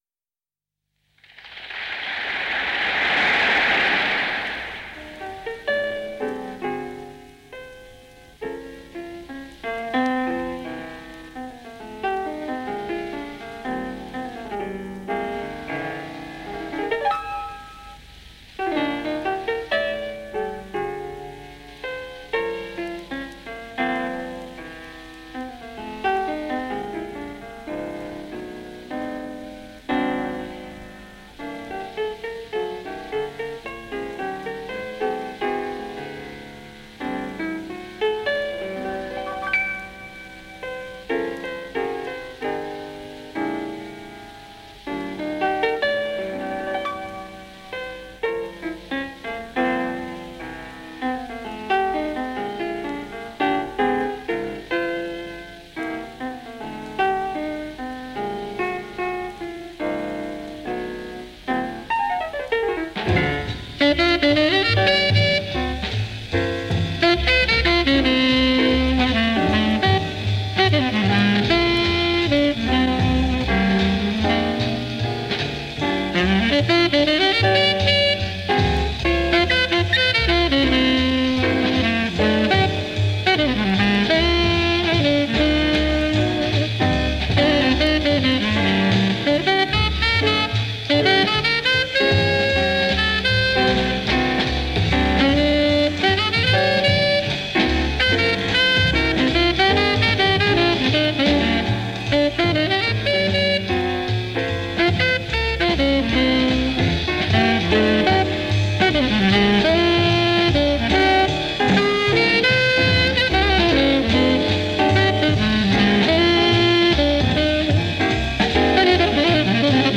Legends of Jazz in concert
tenor sax
Bass
drums